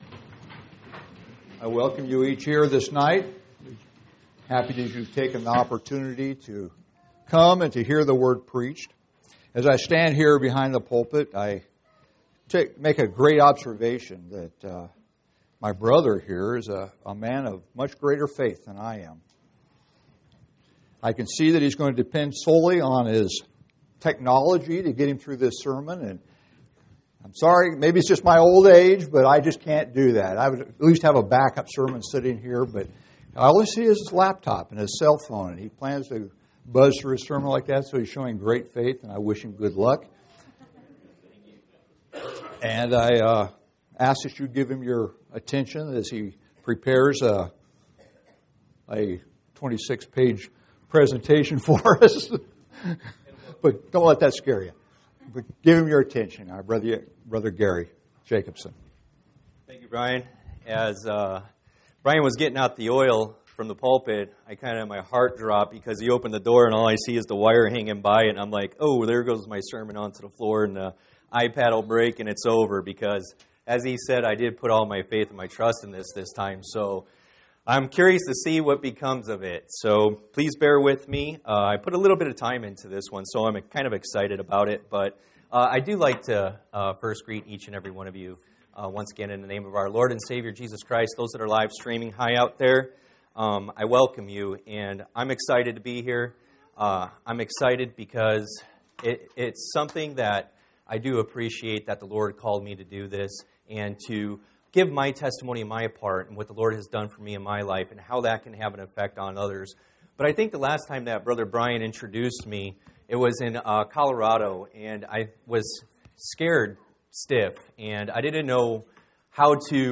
audio-sermons